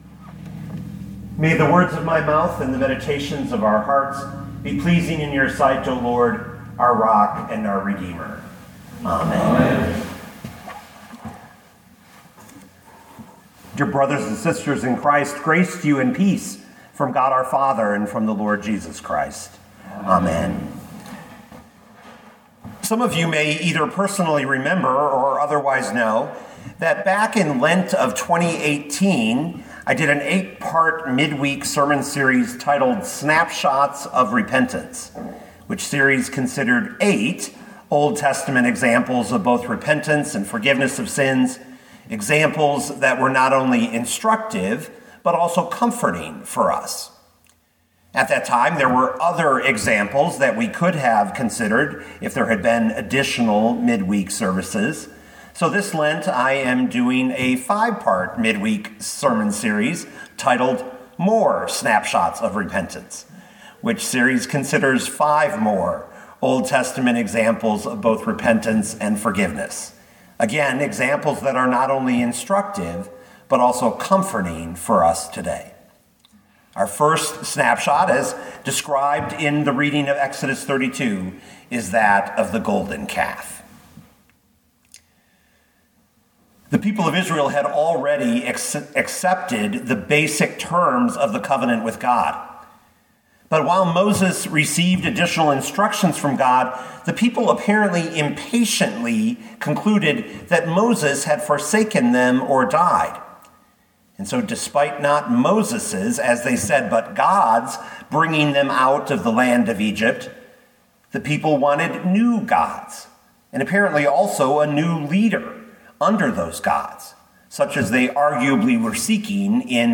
2022 Exodus 32:1-35 Listen to the sermon with the player below, or, download the audio.